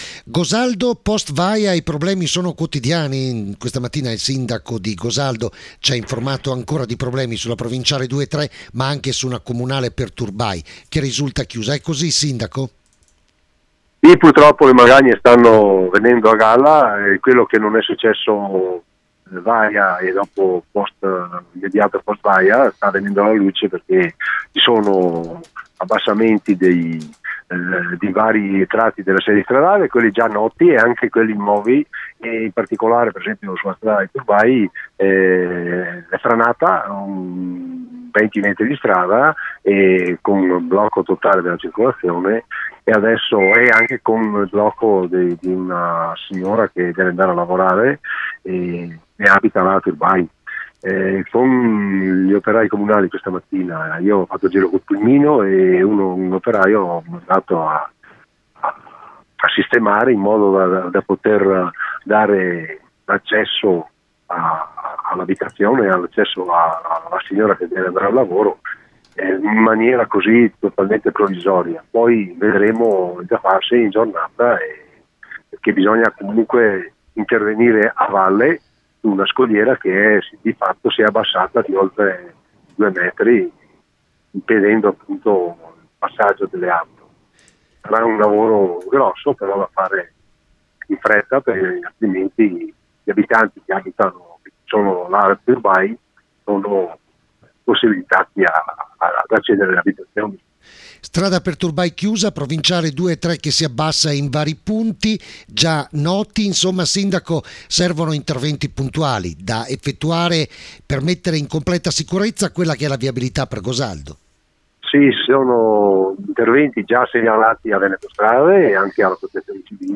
dal sindaco di GOSALDO, Giocondo Dalle Feste